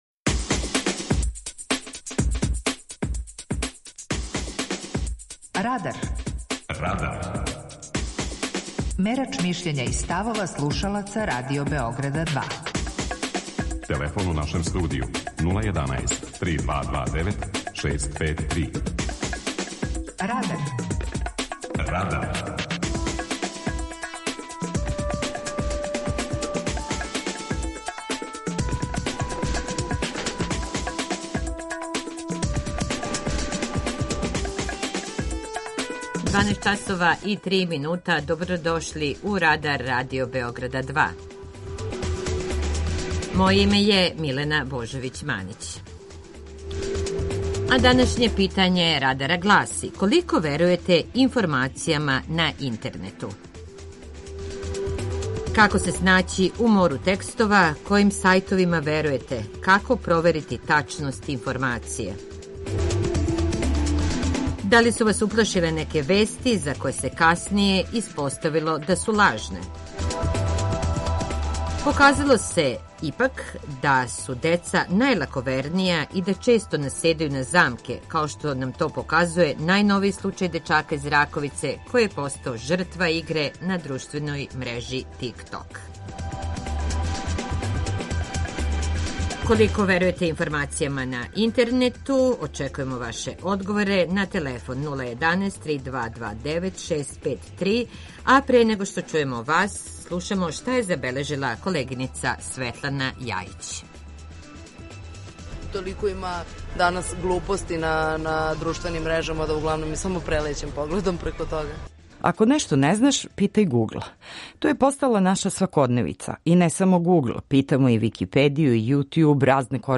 Питање Радара је: Колико верујете информацијама на интернету? преузми : 18.52 MB Радар Autor: Група аутора У емисији „Радар", гости и слушаоци разговарају о актуелним темама из друштвеног и културног живота.